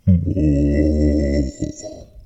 zombie-2.ogg